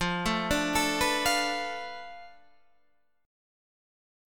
F6b5 Chord